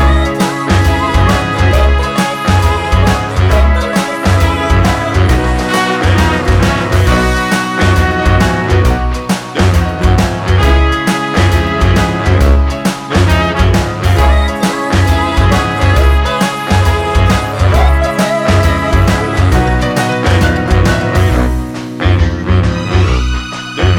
for Duet Pop (2000s) 3:09 Buy £1.50